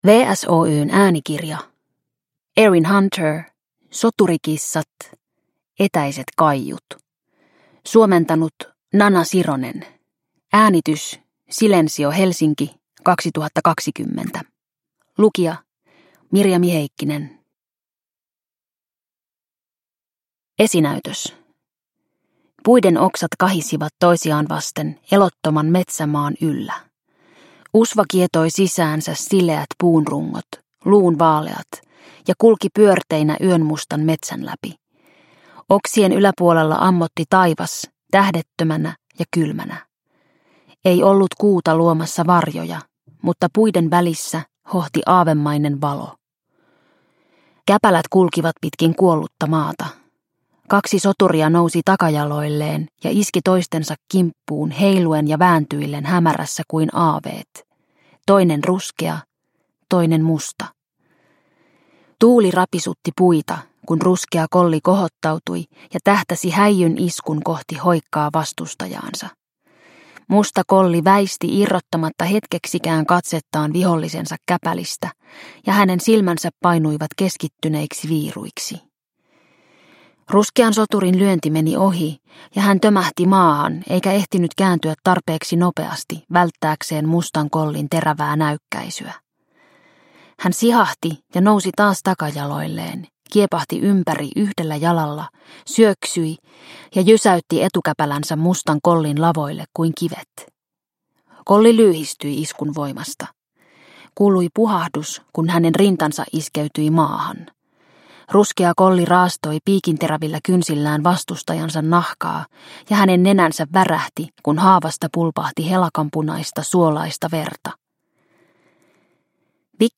Soturikissat: Tähtien enne 2: Etäiset kaiut – Ljudbok – Laddas ner